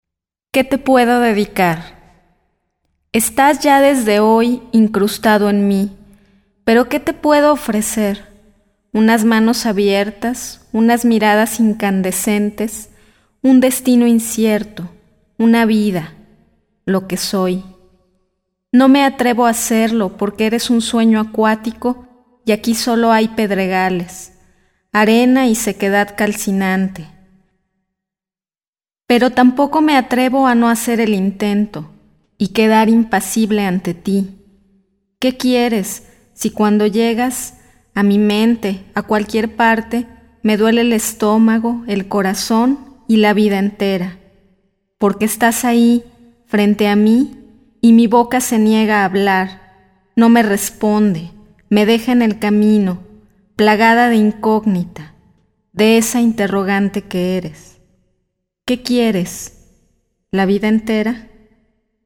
Haga clic para escuchar al autor